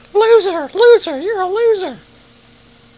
Tags: funny laugh goob voice